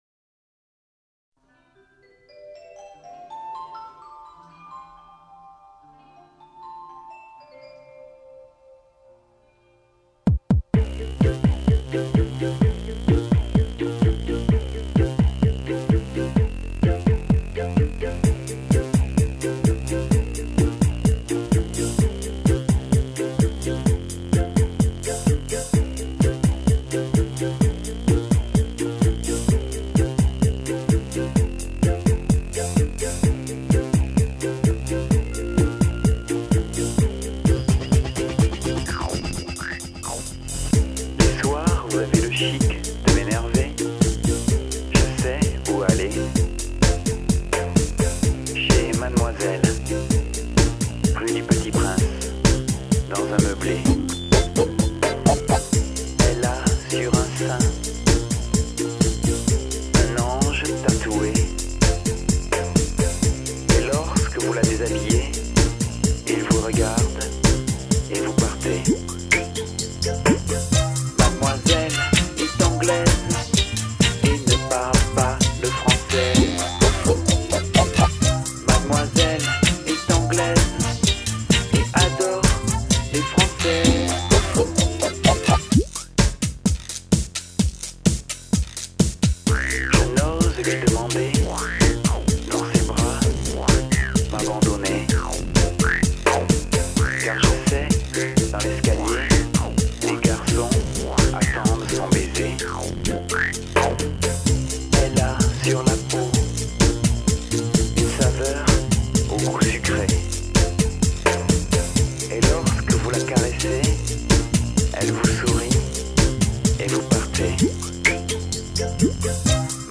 (home version)
Version entièrement réalisée sur mon pc en 2000.